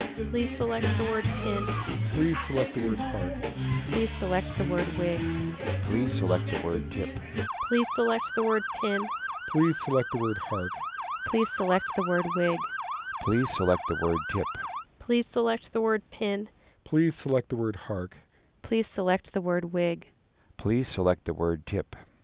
• Opus — Internet Engineering Task Force (IETF) Opus Interactive Audio Codec.
• Narrowband — Supported audio bandwidth has a nominal upper limit between 3 and 4 kHz.
• Club — Speech combined with recorded nightclub noise at 0 dB signal-to-noise ratio (SNR).
• Siren — Speech combined with recorded fire truck siren at 0 dB SNR.
• All — Contains club, siren, and quiet versions, in that order.
• Female speaker — “Please select the word pin.”
• Male speaker — “Please select the word hark.”